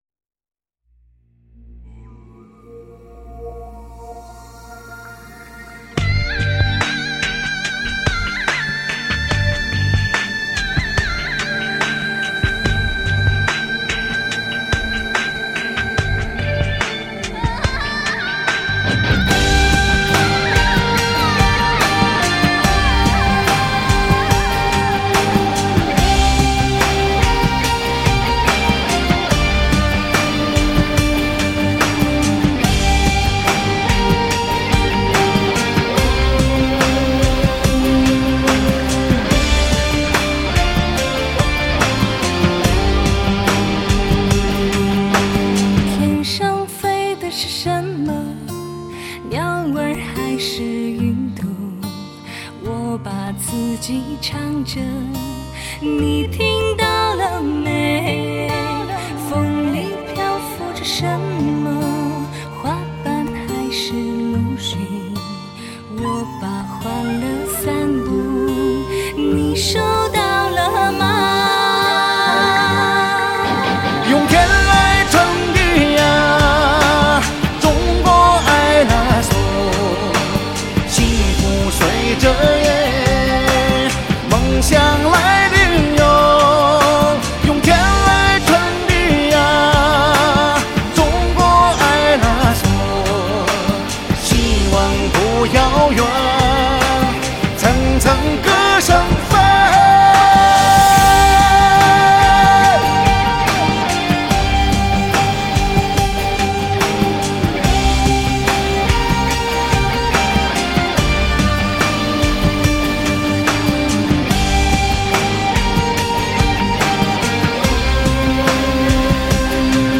时尚民族风来袭 震撼心灵的天籁之音
品味圣洁醇美的高原民族韵味